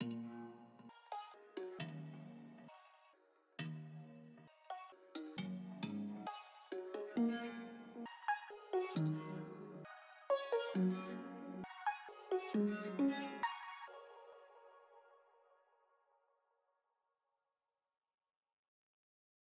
Boomin-Beat-Starter-0_Plucks Gross Beat.wav